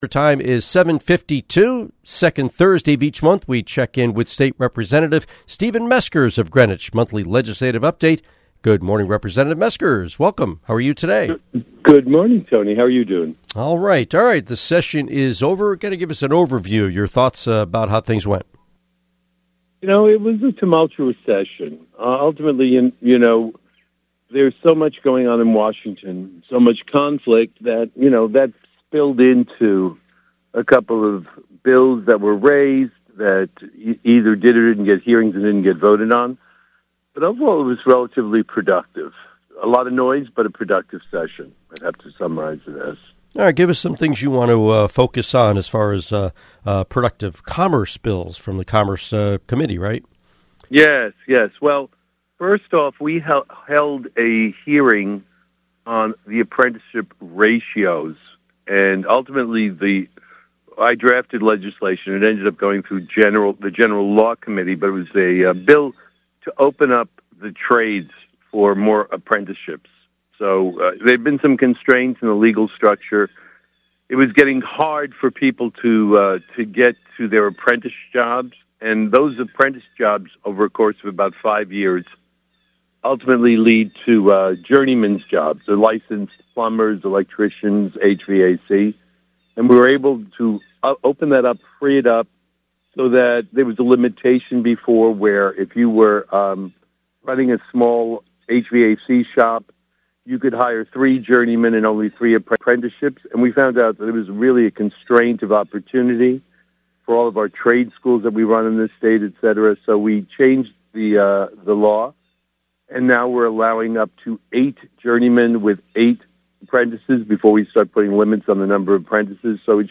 Interview with State Representative Stephen Meskers